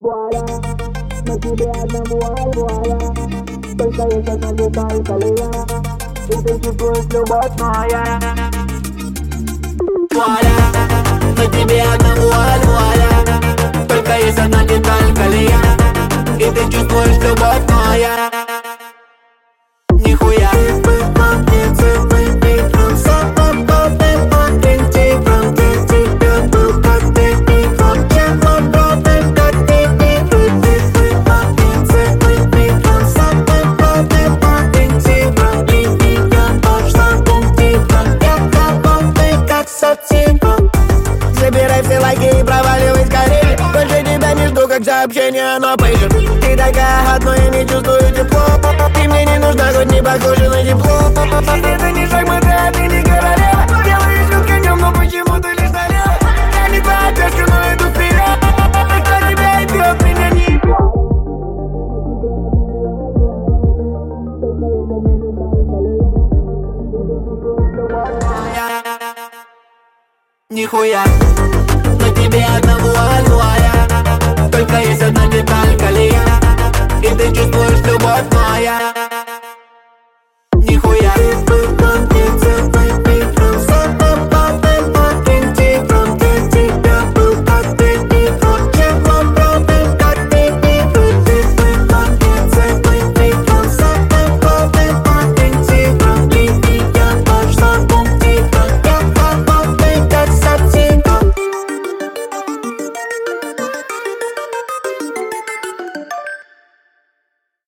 Русский рэп
Жанр: Жанры / Русский рэп